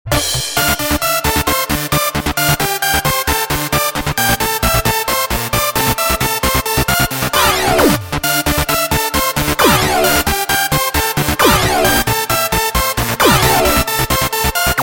Dance
latin , freestyle ,